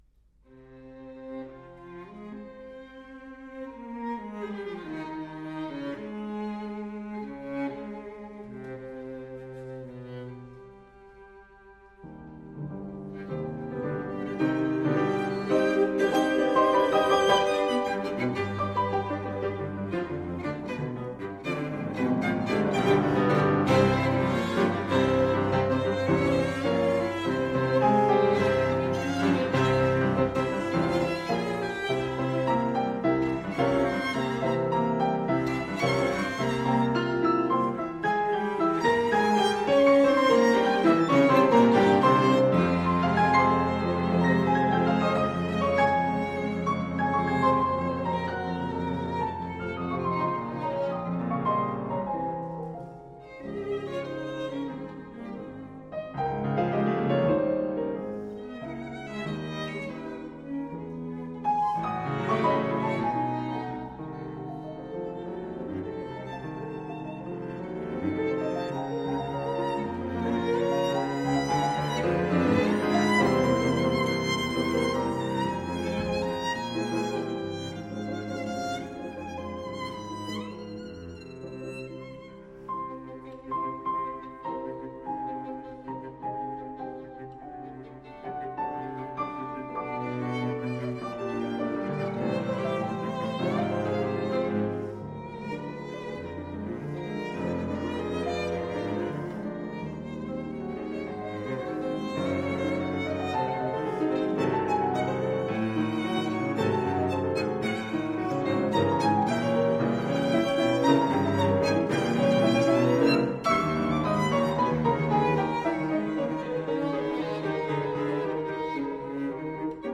Instrument: Piano Trio
Style: Classical
Audio: Boston - Isabella Stewart Gardner Museum
Audio: Claremont Trio (piano trio)
piano-trio-3-op-65.mp3